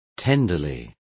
Complete with pronunciation of the translation of tenderly.